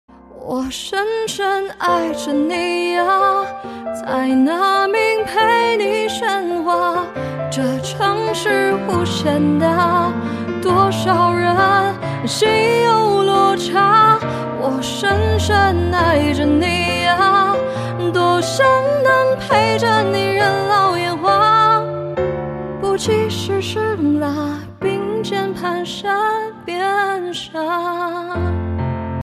M4R铃声, MP3铃声, 华语歌曲 73 首发日期：2018-05-14 07:19 星期一